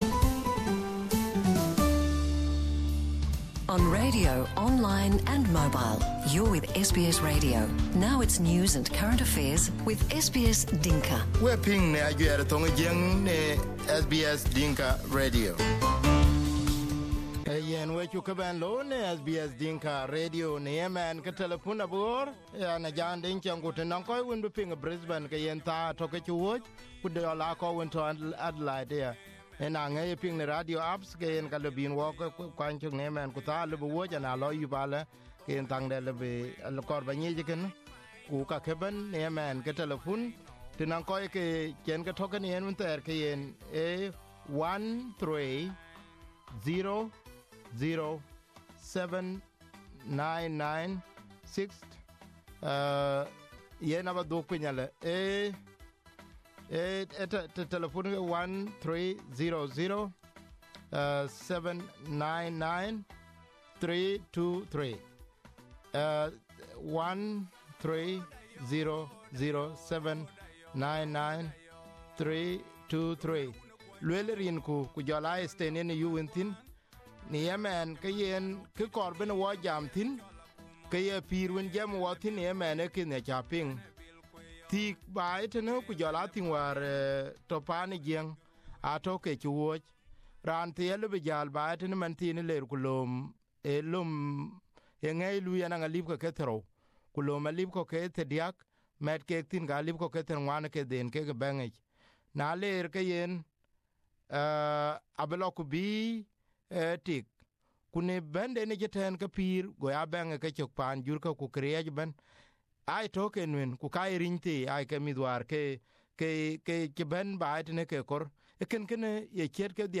On a talkback, three people from Canberra, Melbourne, and Sydney expressed their opinion on SBS Dinka